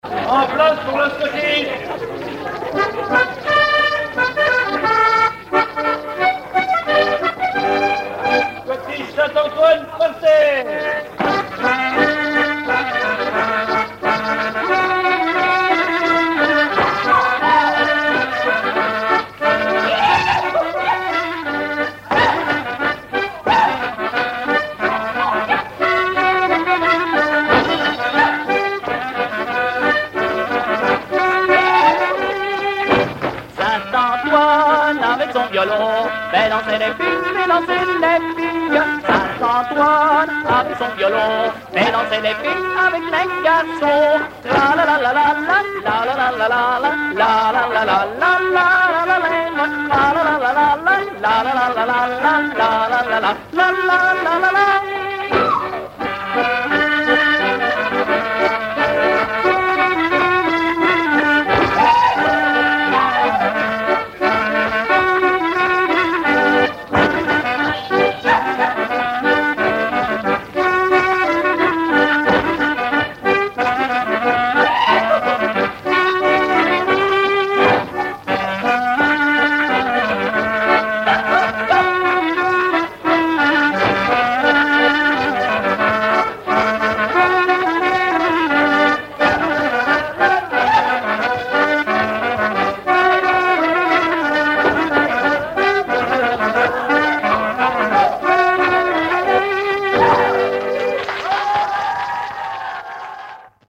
Mémoires et Patrimoines vivants - RaddO est une base de données d'archives iconographiques et sonores.
Chants brefs - A danser
scottich trois pas
Pièce musicale inédite